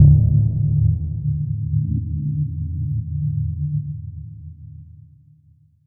Index of /musicradar/impact-samples/Low End
Low End 03.wav